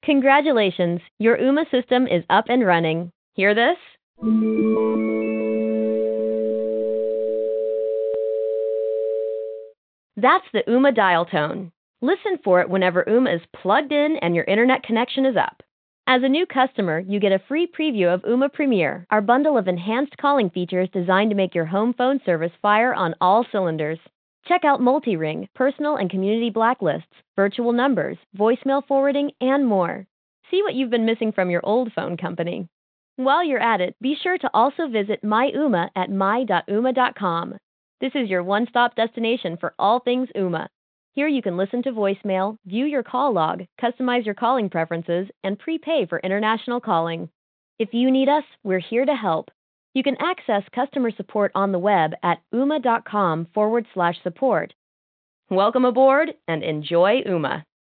Performance-wise, call sound quality was excellent, though we did notice a small hiccup just once, where someone we were having a conversation with noted we dropped out for just a second.
You can check out the voicemail MP3 file that was emailed to me here, upon setup of the Ooma device (I configured Ooma's Premier service to send me voice notes via email).  It will give you a rough idea of call sound quality - Ooma